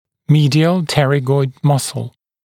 [‘miːdɪəl ˈterɪgɔɪd ‘mʌsl] [‘миːдиэл ˈтэригойд ‘масл] медиальная крыловидная мышца